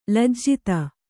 ♪ lajjita